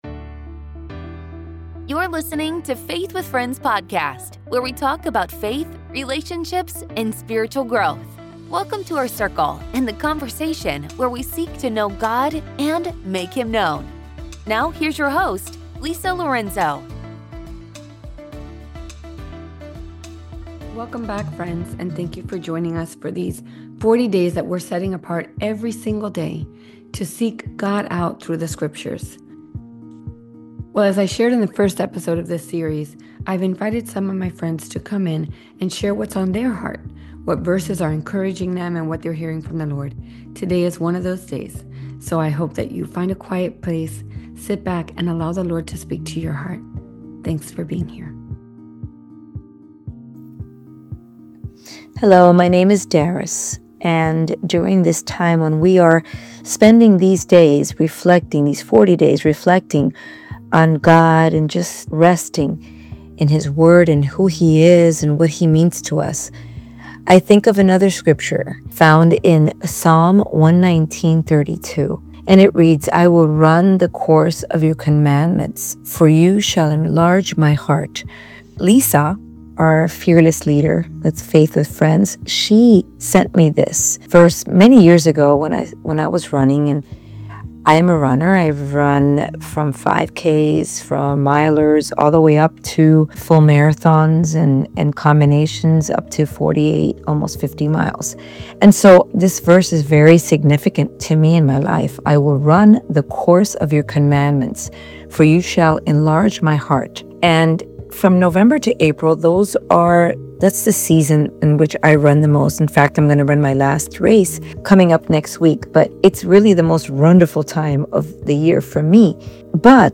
• Divine Intention in Trials: The conversation reassures listeners that obstacles are part of God's intentional design for victory and spiritual deepening.